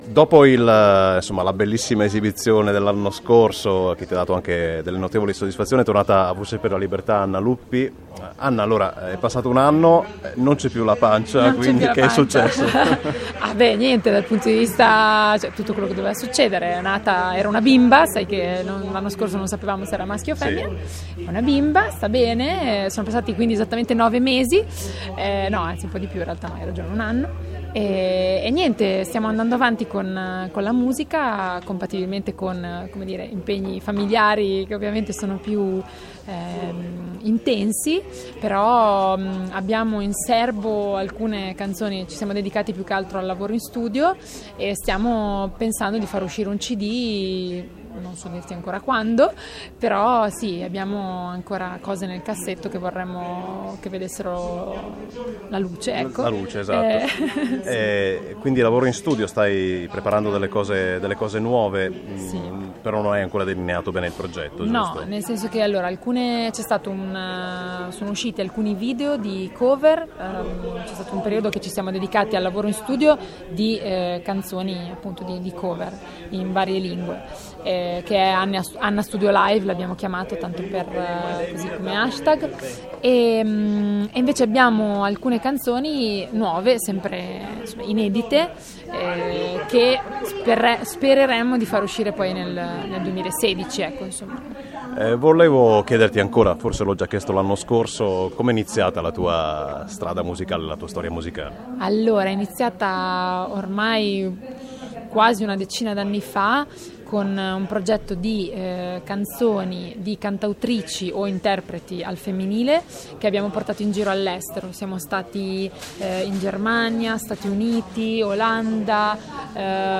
Di seguito i link diretti alle inteviste che abbiamo realizzato durante il festival Voci per la Libertà - Una Canzone per Amnesty 2015.
Intervista